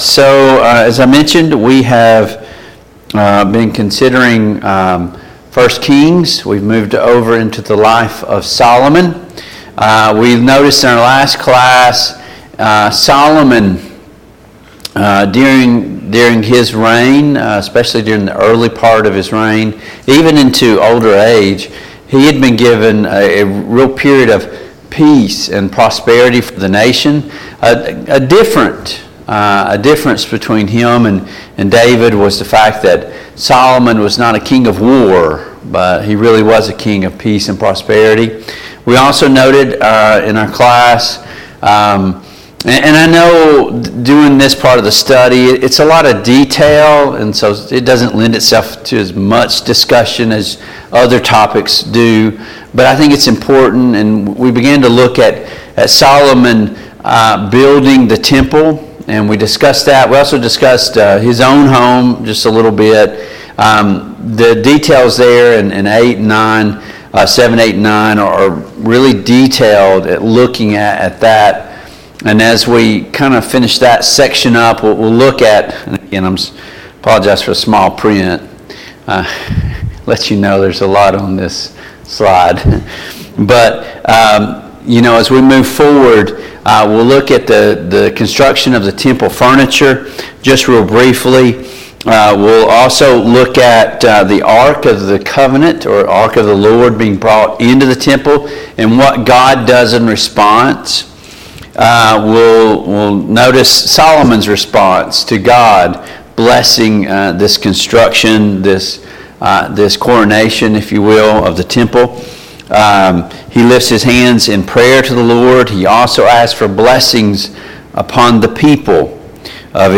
Passage: 1 Kings 7, 1 Kings 8, 1 Kings 9 Service Type: Mid-Week Bible Study